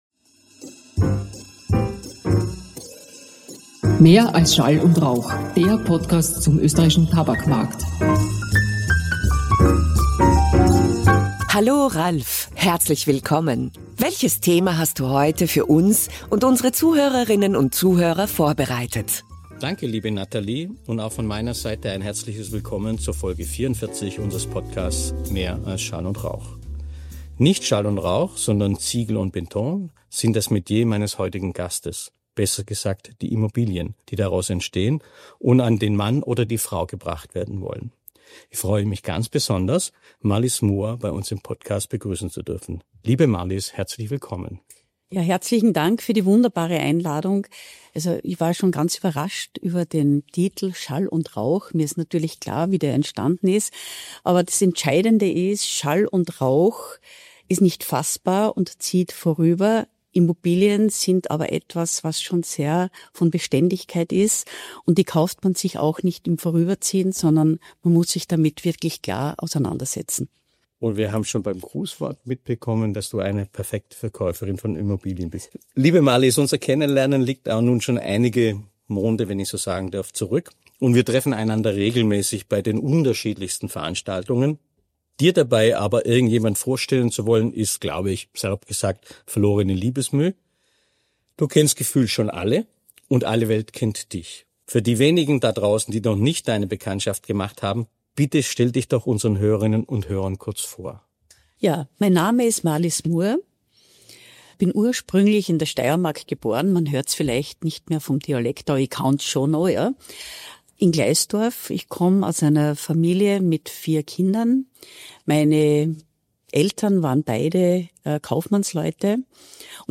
Sie beleuchtet die Herausforderungen der Branche, den Einfluss der Pandemie auf Wohnbedürfnisse und ihre Liebe zur Kultur. Ein Gespräch über Beständigkeit, Leidenschaft und die Kunst, Menschen ein Zuhause zu geben.